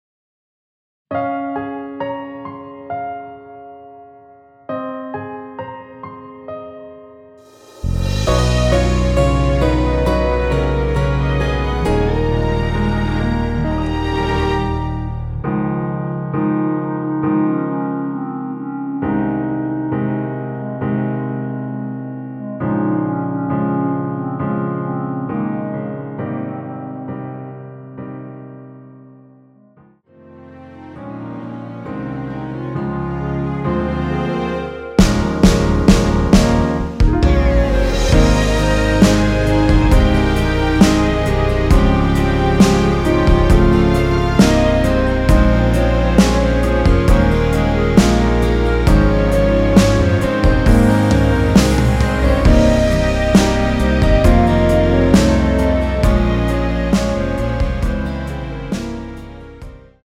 원키에서(-4)내린 1절앞+후렴으로 진행되는 멜로디 포함된 MR입니다.(미리듣기 확인)
Ab
앞부분30초, 뒷부분30초씩 편집해서 올려 드리고 있습니다.
중간에 음이 끈어지고 다시 나오는 이유는